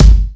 Funkrap.wav